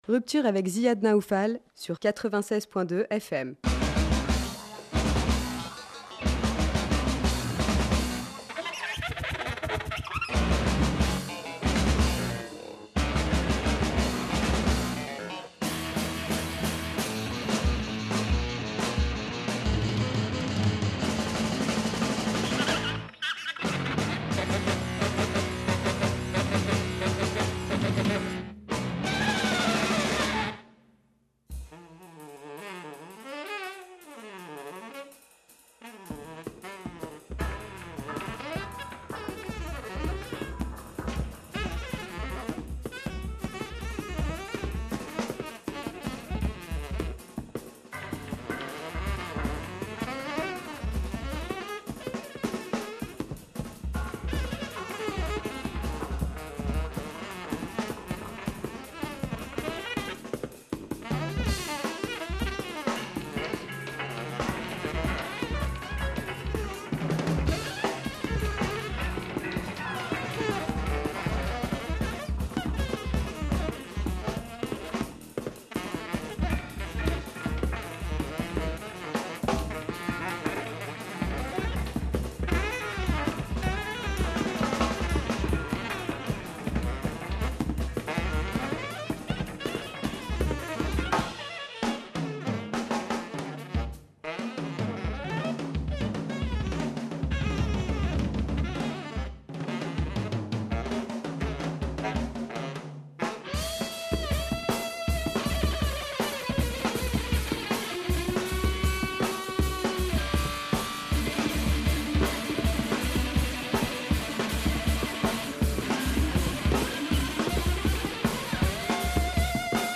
improvisation trio
bassist and clarinet player
oud player
guitarist
radio program for an interview and live performance